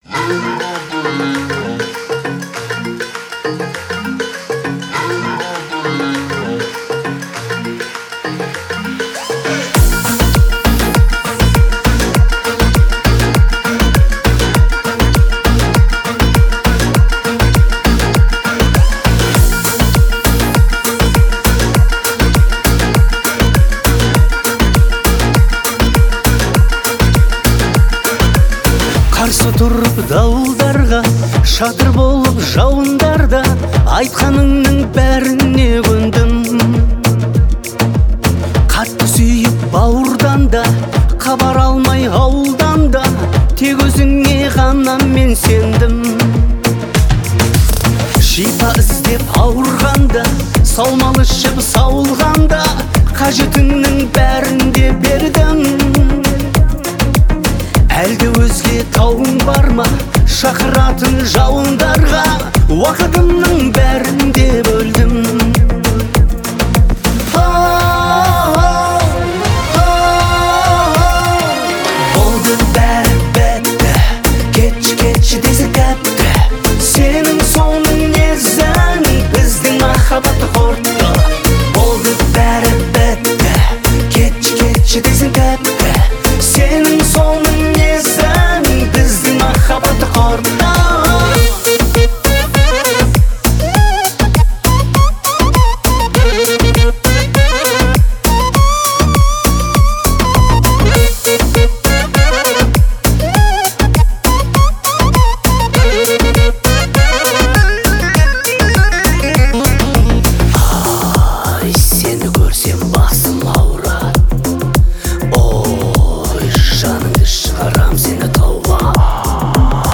своим сильным и выразительным вокалом